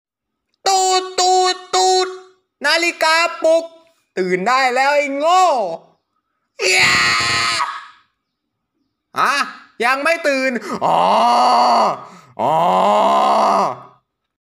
Genre: เสียงนาฬิกาปลุก